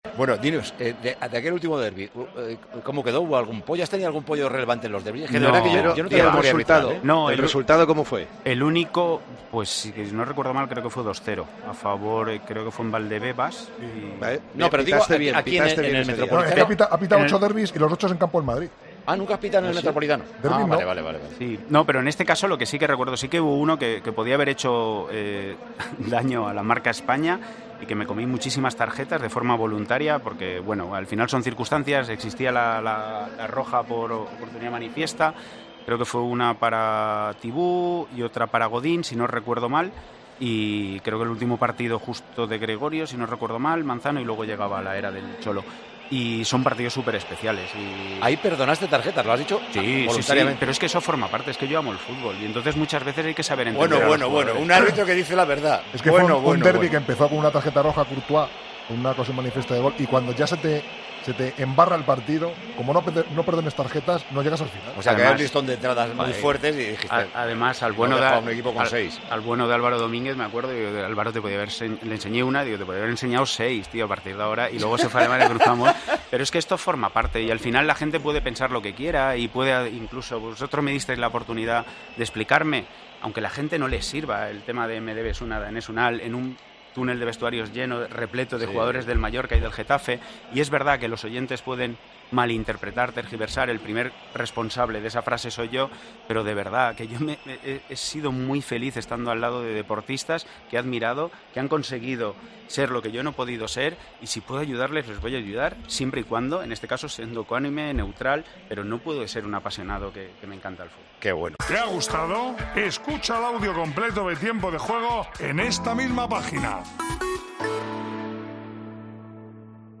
Mateu Lahoz debutó este domingo como comentarista arbitral en Tiempo de Juego y sorprendió con numerosas anécdotas y una de ellas provocó la reacción de Paco González.
Durante la retrasmisión del encuentro, Mateu desveló numerosas anécdotas de su etapa como árbitro y confesó lo que hizo en el trascurso de un derbi entre colchoneros y madridistas y que provocó la sorpresa de Paco González: "Bueno, bueno...".